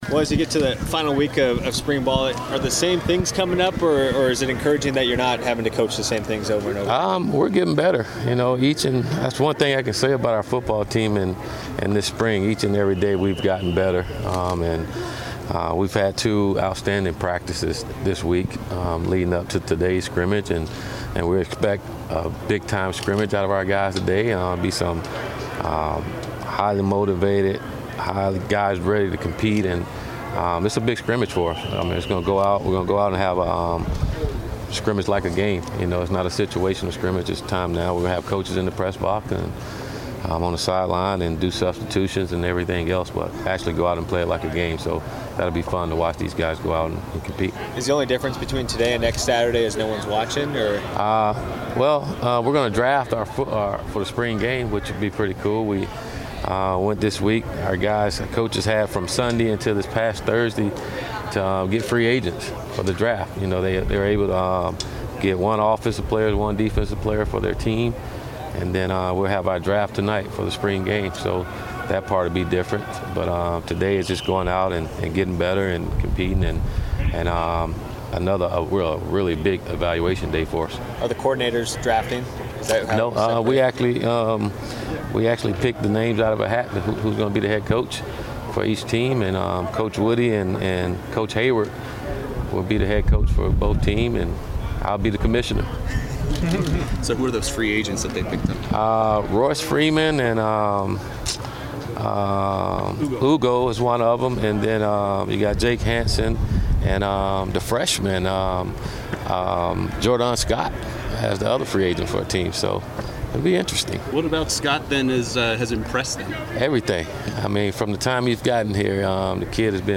Willie Taggart speaks with the media before Oregon's scrimmage.